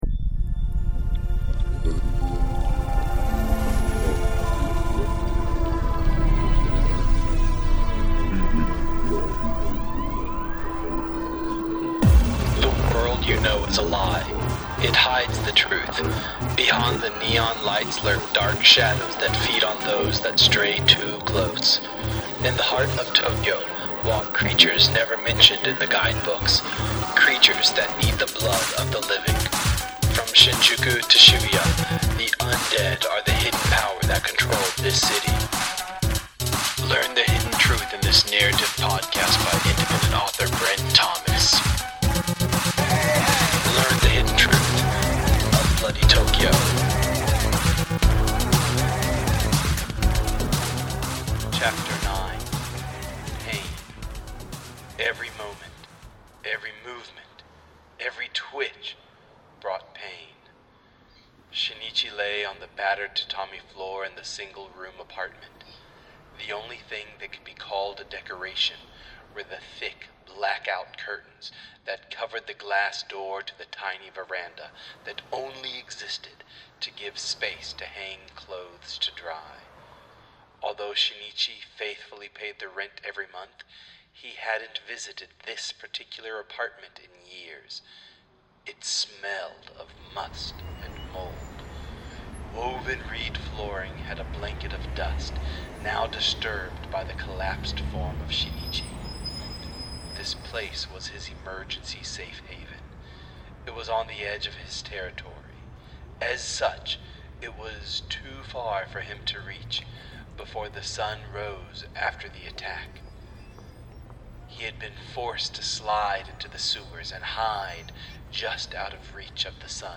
Bloody Tokyo features original music composed by Manny Marx.